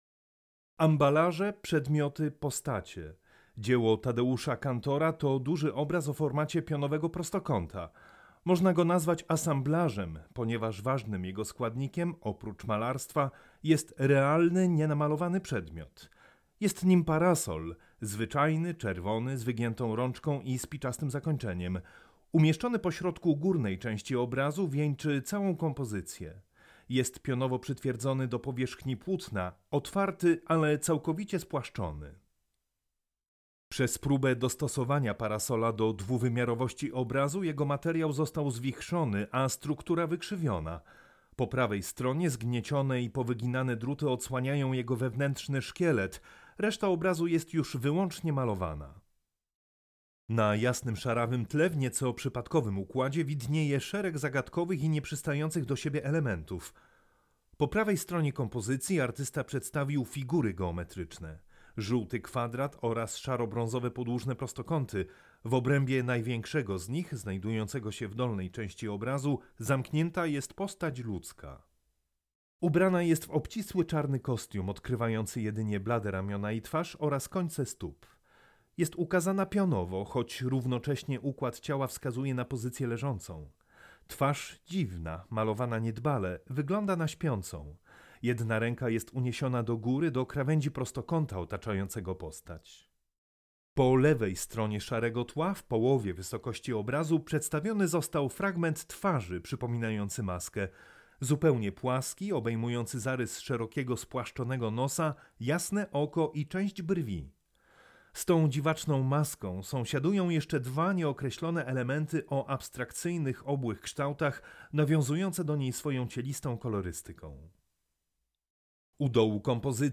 AUDIODESKRYPCJA
AUDIODESKRYPCJA-Tadeusz-Kantor-Ambalaze-przdmioty-postacie.mp3